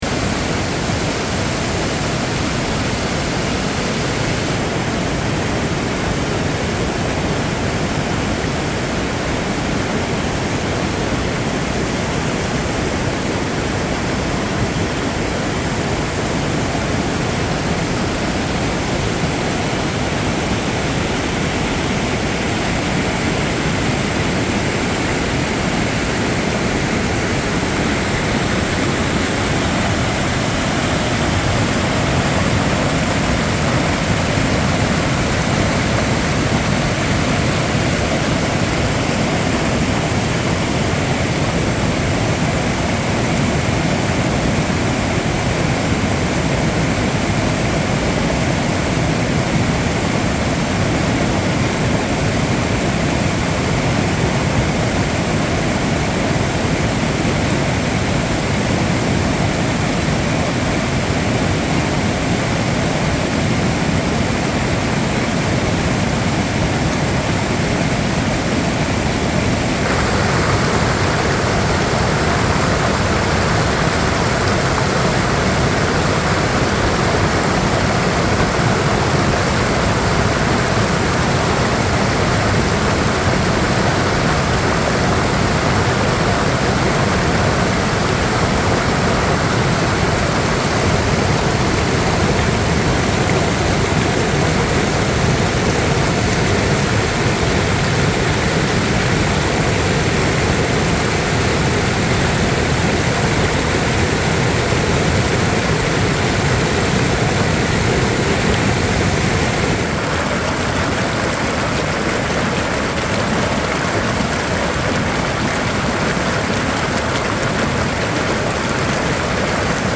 Шум разных водопадов для монтажа в mp3 формате
19. Шум альпийского горного водопада
gorn-vodop-al.mp3